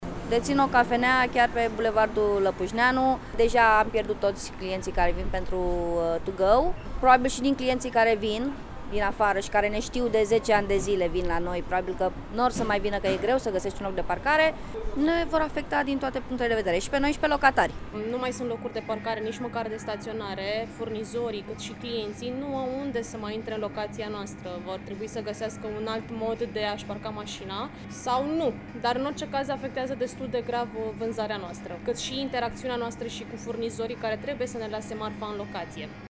Comercianții din zonă spun că afacerile le-au fost afectate încă din prima zi: